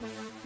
Synth (Slow It Down).wav